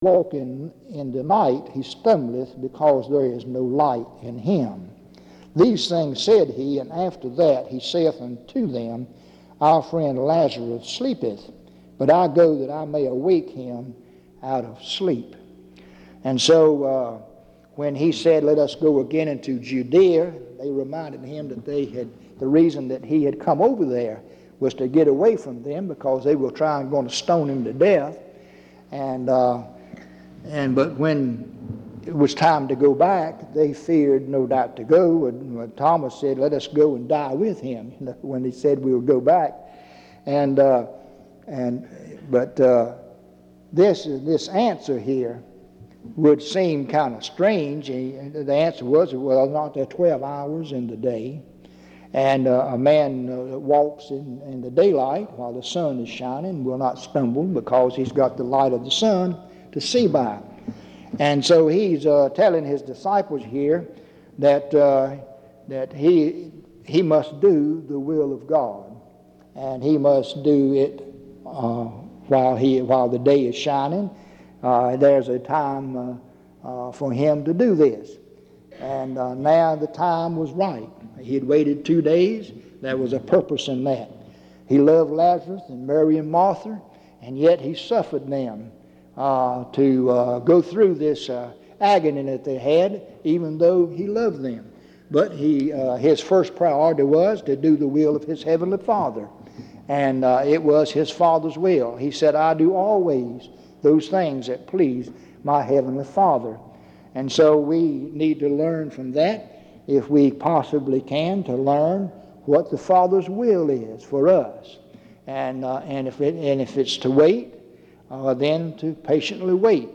En Collection: Reidsville/Lindsey Street Primitive Baptist Church audio recordings Miniatura Título Fecha de subida Visibilidad Acciones PBHLA-ACC.001_013-A-01.wav 2026-02-12 Descargar PBHLA-ACC.001_013-B-01.wav 2026-02-12 Descargar